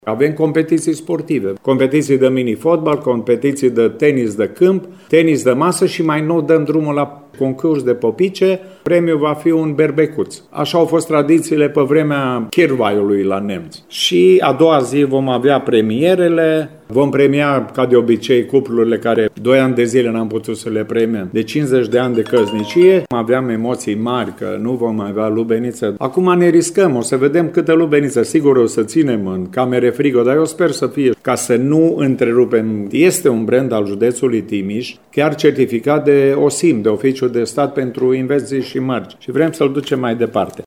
Evenimentele din acest an marchează și 250 de ani de la atestarea documentară a localității, spune primarul Gheorghe Nastor.